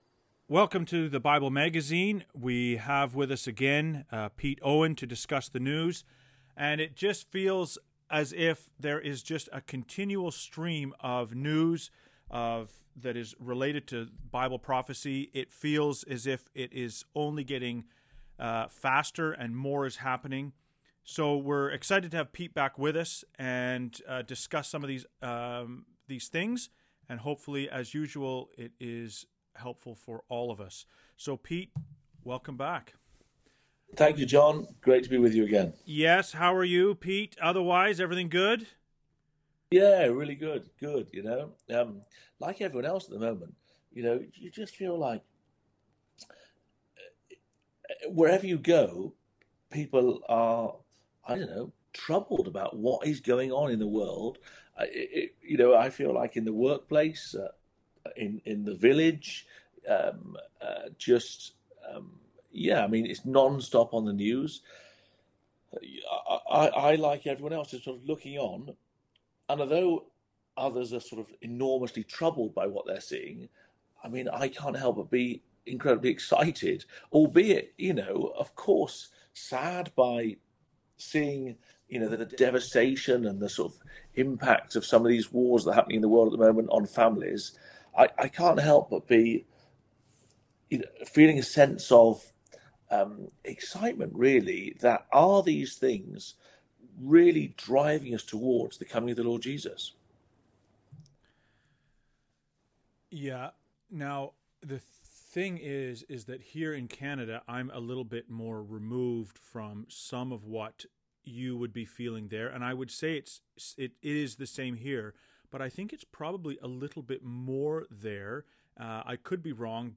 BIG NEWS! Is Peace Coming to the Mountains of Israel??? An Unscripted! conversation on current events in light of Bible Prophecy.